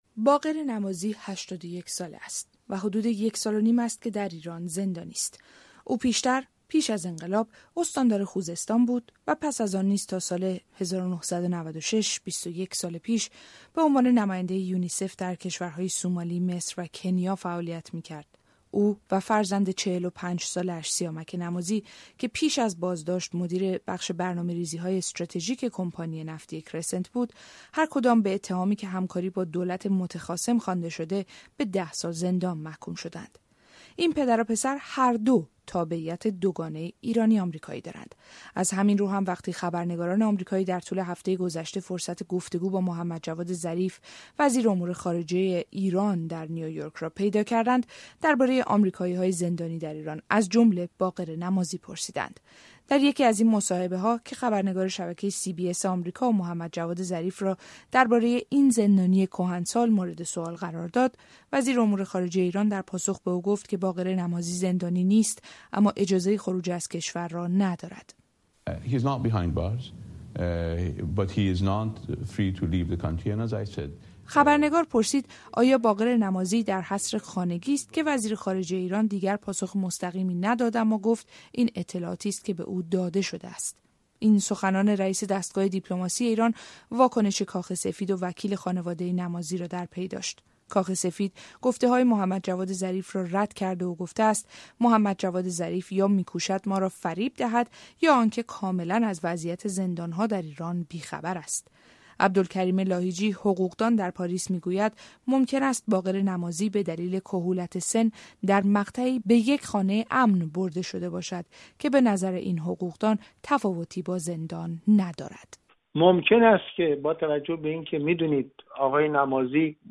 پس از آنکه وزیر امور خارجه ایران گفت باقر نمازی در زندان نیست و تنها نمی‌تواند از کشور خارج شود، کاخ سفید اظهارات او را رد کرد و گفت که محمدجواد ظریف یا می‌کوشد ما را فریب دهد یا اینکه کاملاً از وضعیت زندان‌ها در ایران بی‌خبر است. گزارشی در این باره همراه با دیدگاه عبدالکریم لاهیجی.